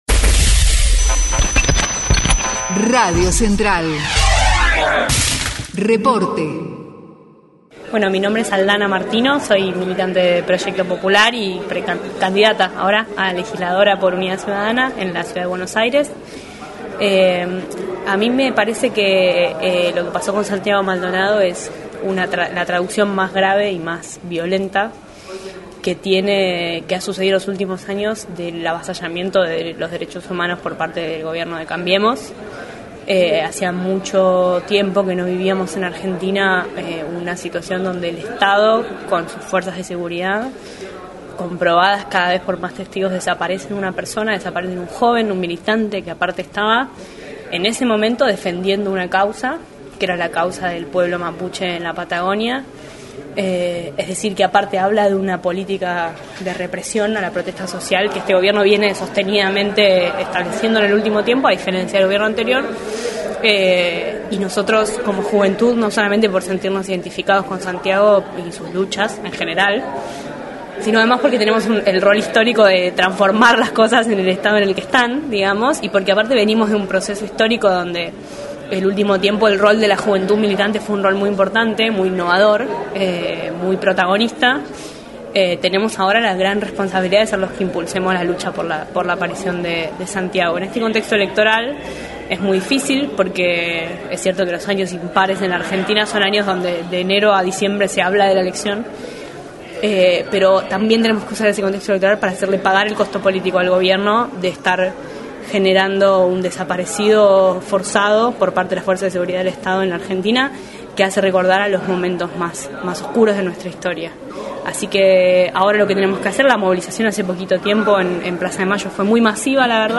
Encuentro de juventud en la CTA por la aparición con vida de Santiago Maldonado